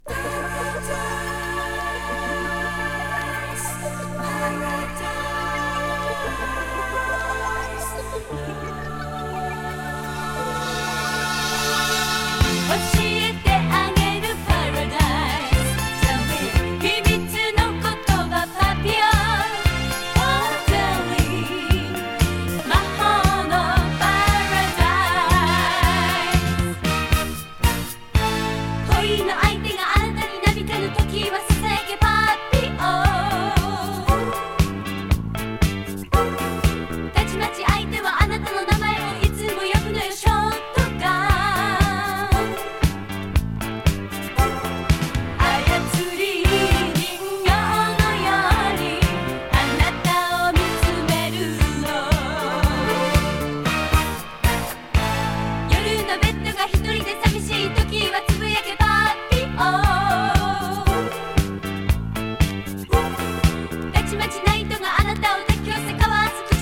Japanese City Pop / AOR レコード